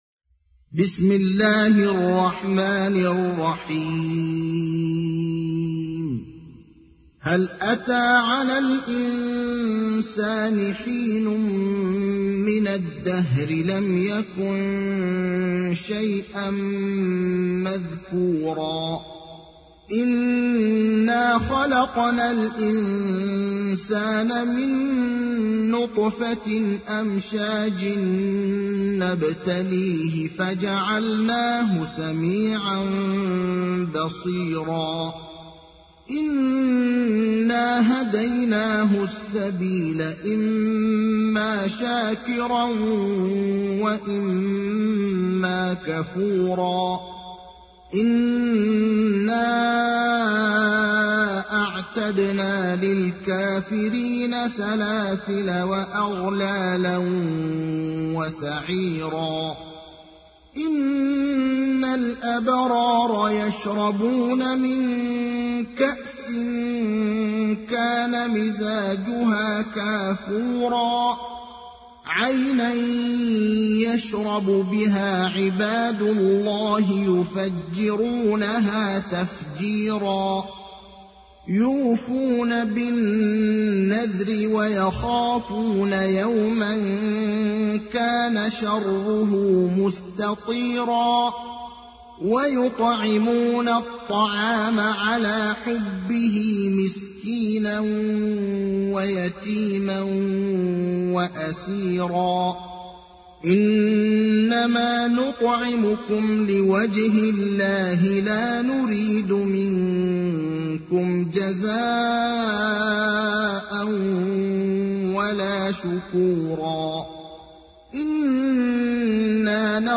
تحميل : 76. سورة الإنسان / القارئ ابراهيم الأخضر / القرآن الكريم / موقع يا حسين